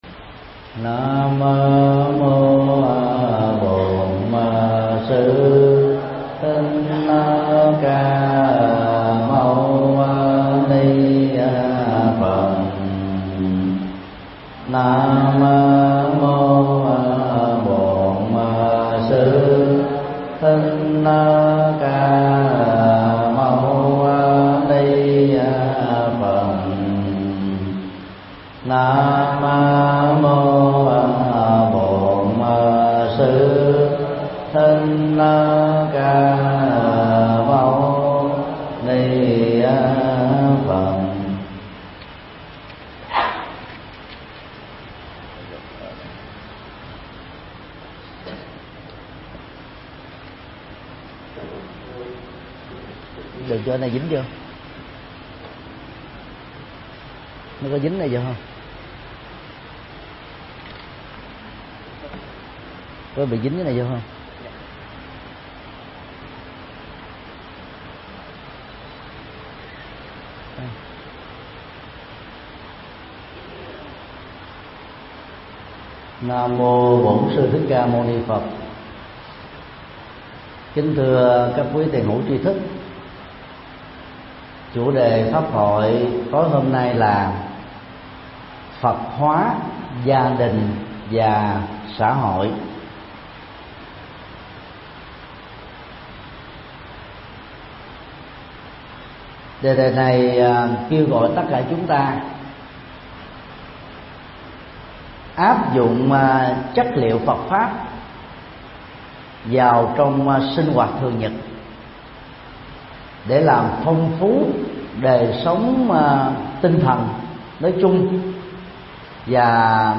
Mp3 Pháp Thoại Phật hóa gia đình và xã hội (Bản Live Stream) – Thầy Thích Nhật Từ ngày 6 tháng 12 năm 2015